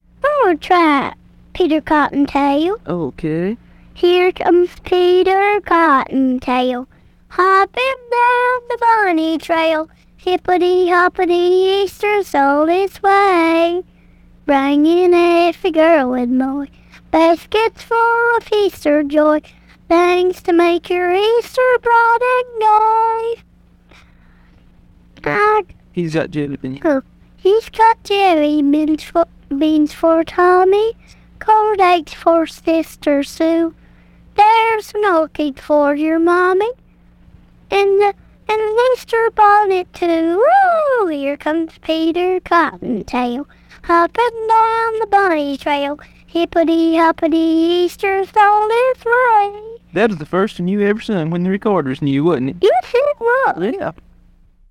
Now here's the same recording, run through Adobe's speech enhancer AI tool.